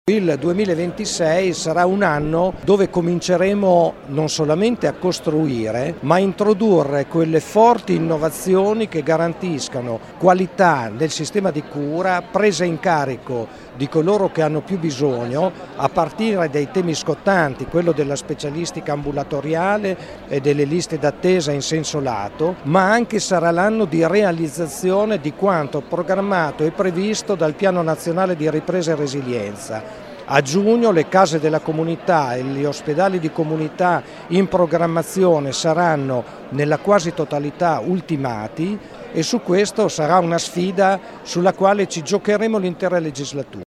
Ad un incontro pubblico a Parma, l’assessore regionale alla sanità Massimo Fabi ha fatto grandi promesse: